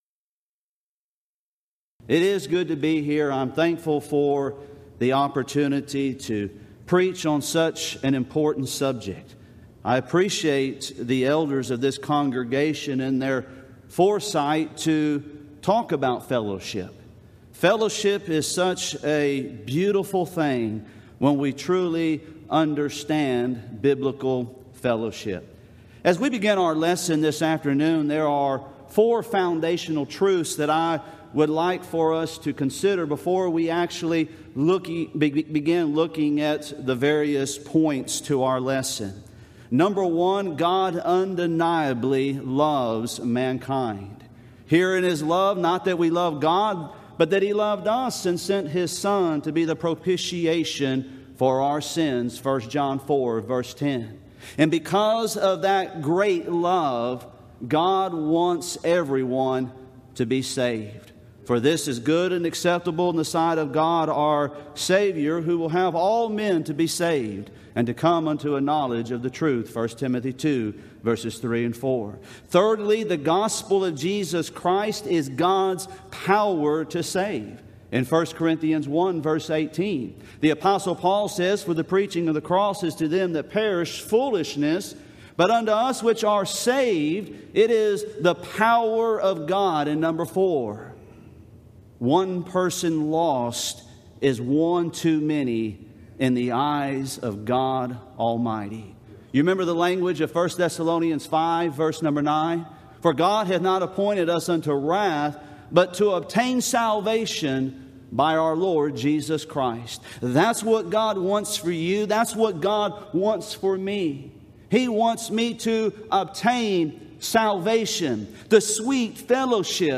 Event: 24th Annual Gulf Coast Lectures Theme/Title: Christian Fellowship
lecture